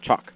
chalk.mp3